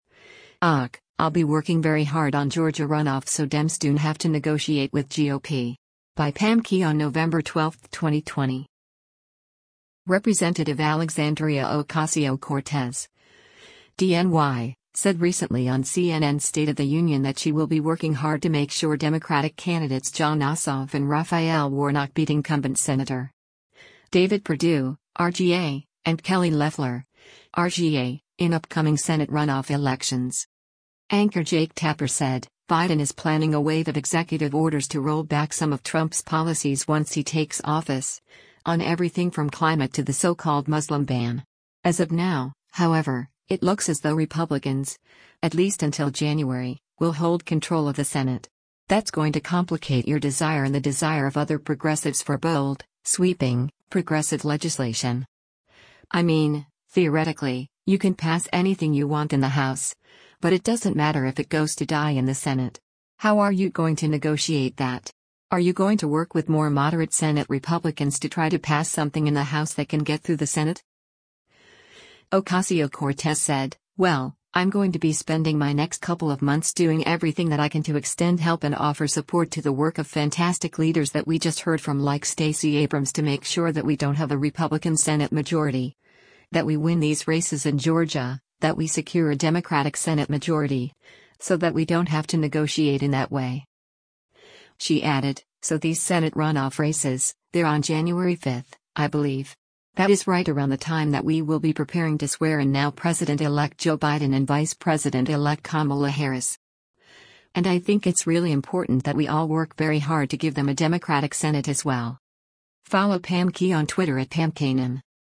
Representative Alexandria Ocasio-Cortez (D-NY) said recently on CNN’s “State of the Union” that she will be working hard to make sure Democratic candidates Jon Ossoff and Raphael Warnock beat incumbent Senator.